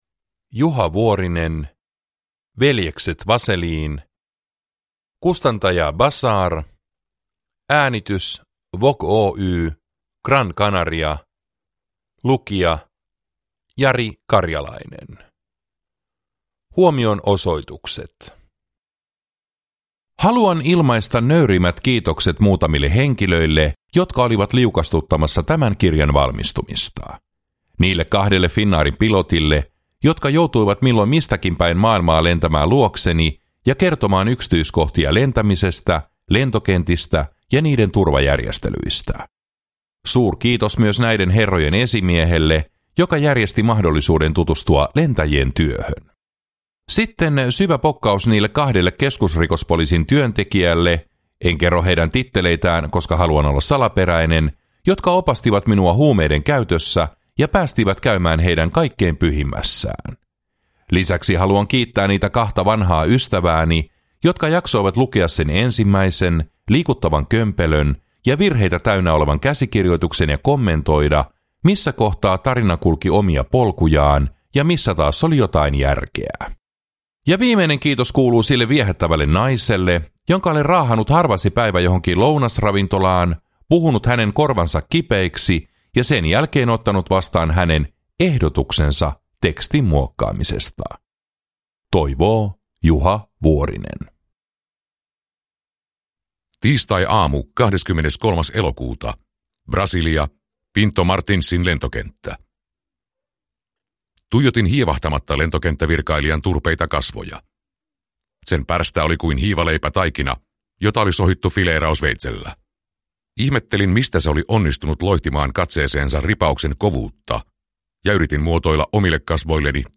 Veljekset Vaselin – Ljudbok